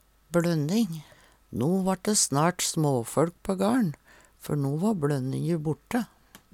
ng-lyden i bLønninga er ikkje rein ng-lyd, ly til lydfil